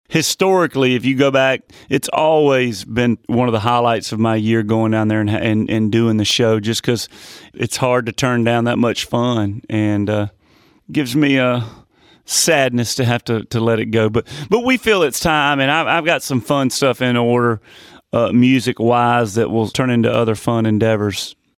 Audio / Luke Bryan says one of his favorite parts of the year has been doing the Spring Break shows in Panama City.